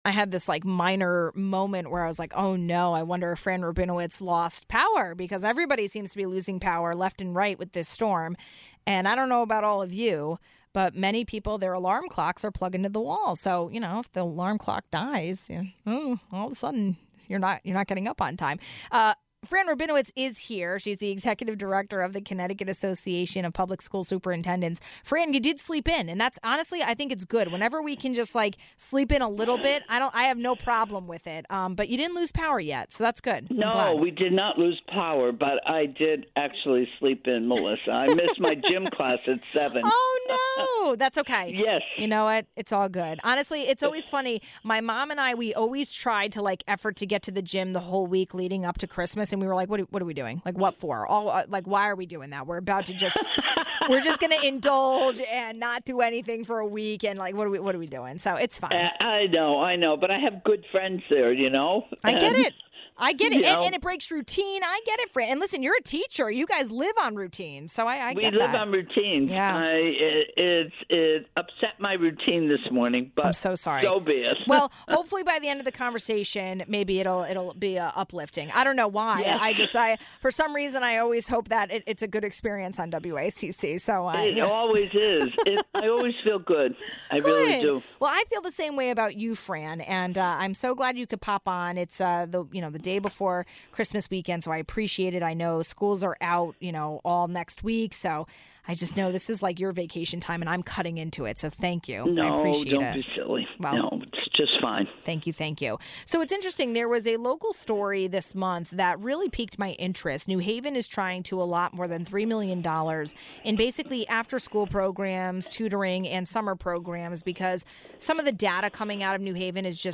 Governor Lamont stopped by one last time in 2022. We took listener questions and did a deep dive into education funding. The governor spoke on inner city support including tutoring, summer programs, working conditions and teacher recruitment.